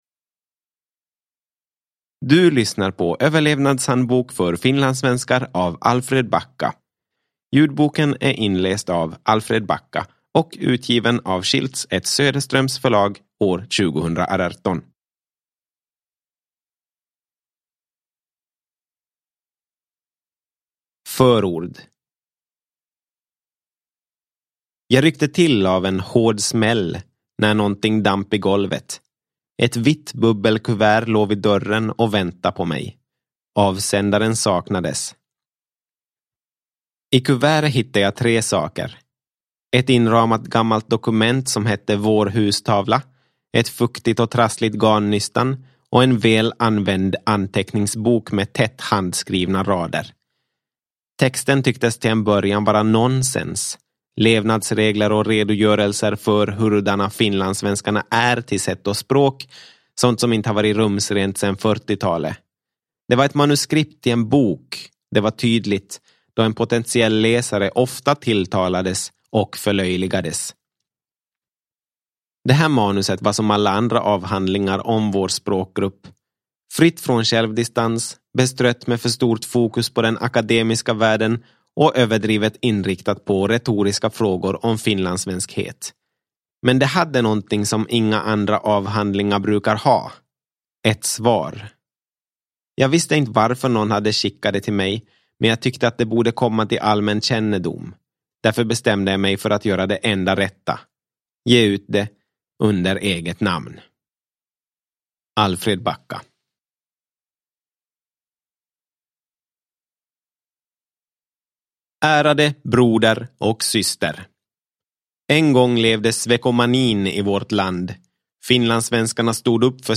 Överlevnadshandbok för finlandssvenskar – Ljudbok – Laddas ner